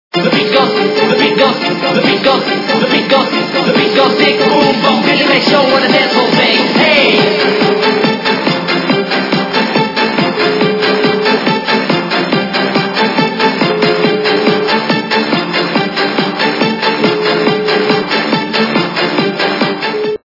- западная эстрада
При заказе вы получаете реалтон без искажений.